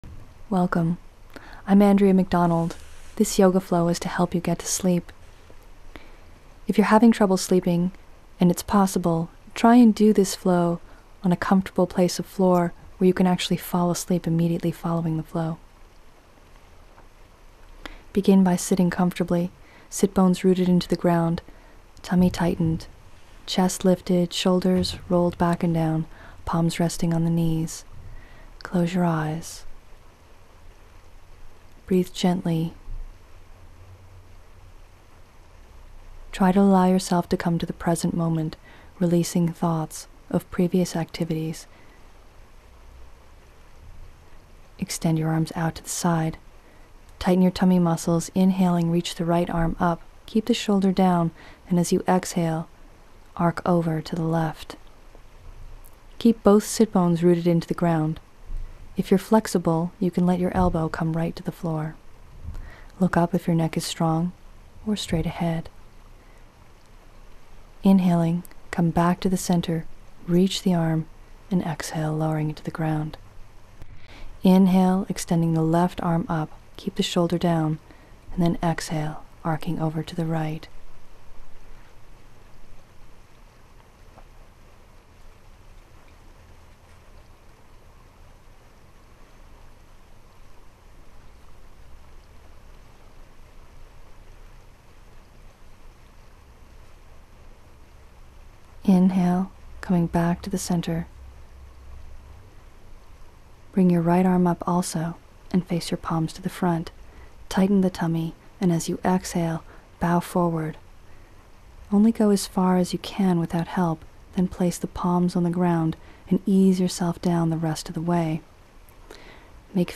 Yoga for Better Sleep — Full 35-Minute Class _ Deep Relaxation & Insomnia Relief.mp3